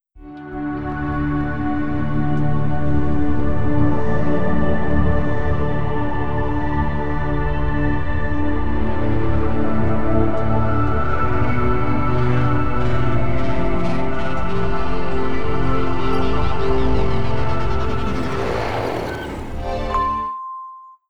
XBOX One Startup (Motion Brand Identity [2015]).wav